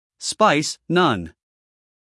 英音/ spaɪs / 美音/ spaɪs /